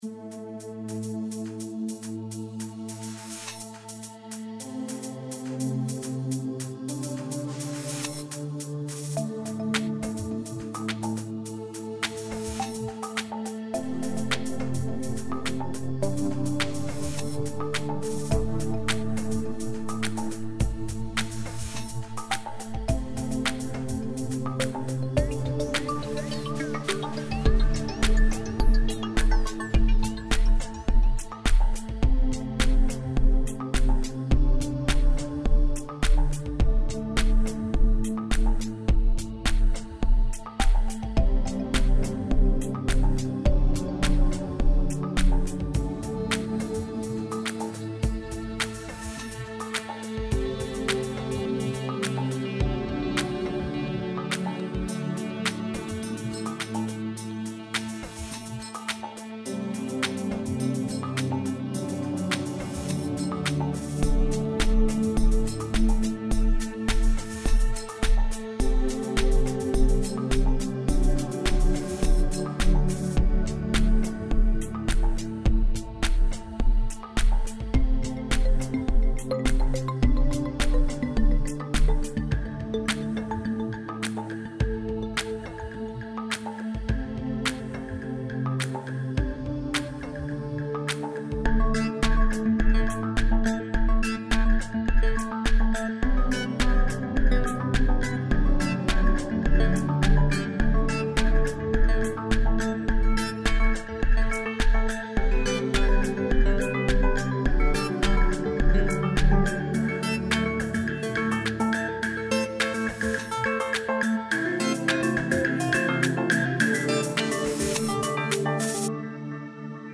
Game music